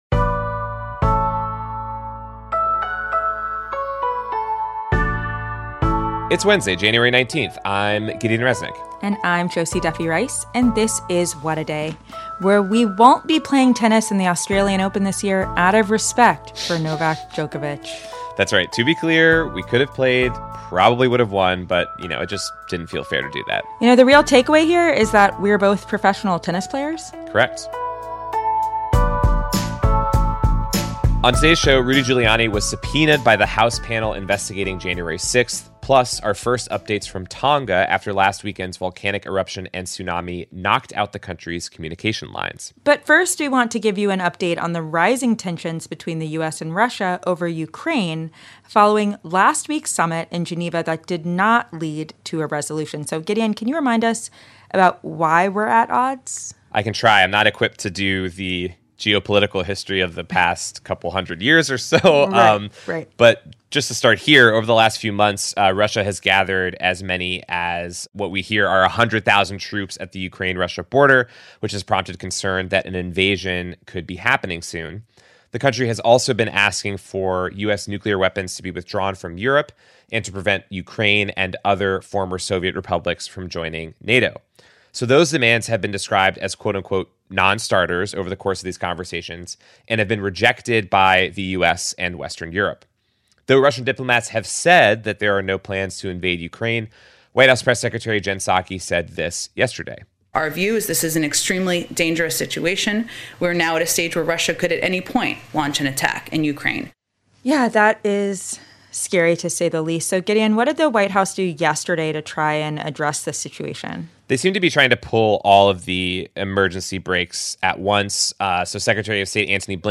Tensions between the U.S. and Russia are rising after last week’s summit in Geneva ended with no resolution between the two countries over Ukraine. We hear an excerpt of Secretary of State Antony Blinken’s exclusive interview with Pod Save The World just hours before he went overseas yesterday to meet with Ukraine’s president and Russia’s foreign minister.